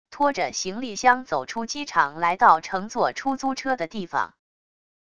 托着行李箱走出机场来到乘坐出租车的地方wav音频